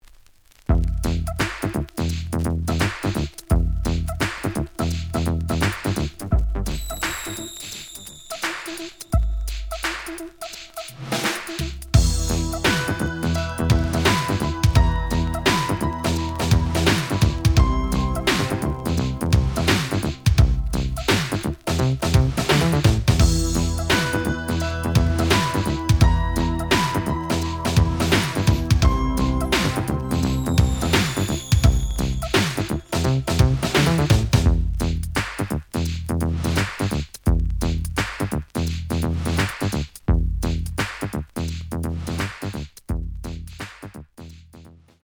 (Instrumental)
The audio sample is recorded from the actual item.
●Format: 7 inch
●Genre: Hip Hop / R&B